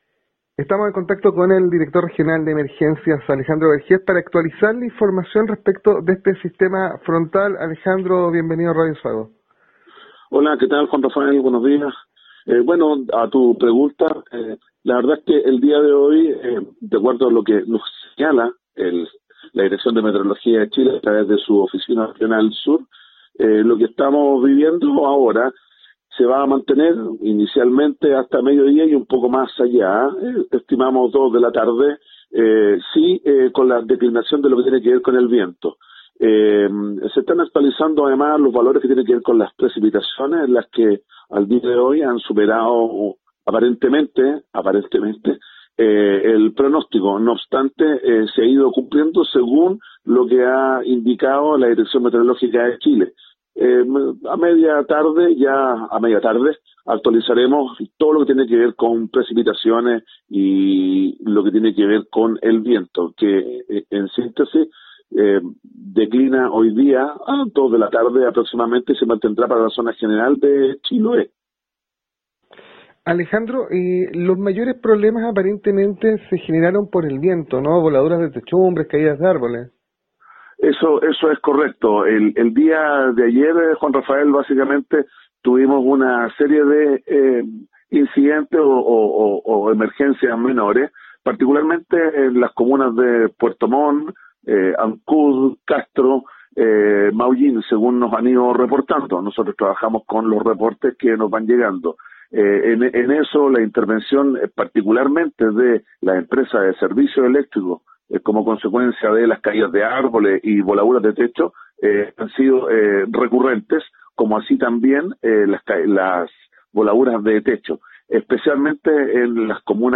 En conversación con Radio Sago, el director regional de la oficina nacional de emergencias, ONEMI, Alejandro Vergés indicó que ya pasó la mayor intensidad del